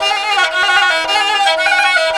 Index of /90_sSampleCDs/Sonic Foundry (Sony Creative Software) - World Pop/Stringed Instruments/Chinese